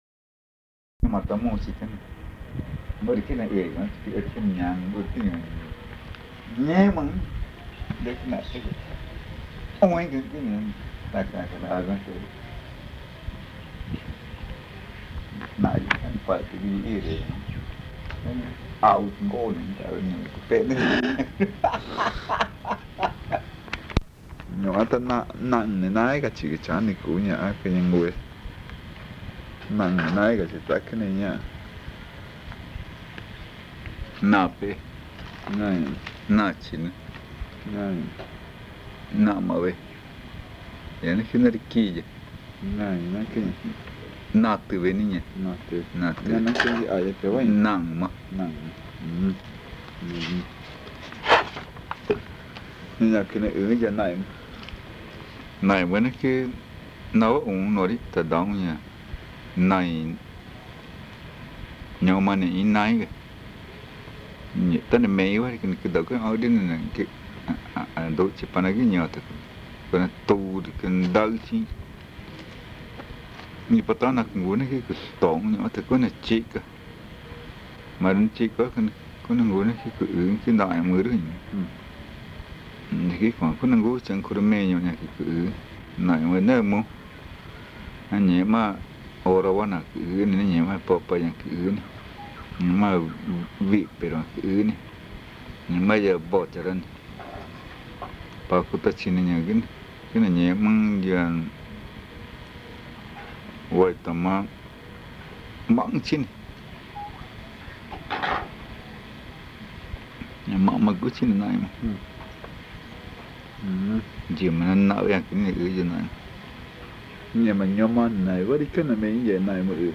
Este casete es el segundo de una serie de tres casetes que se grabaron en torno a la variedad magütá hablada en Arara. El audio contiene los lados A y B.